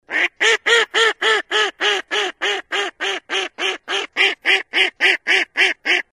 Дикая утка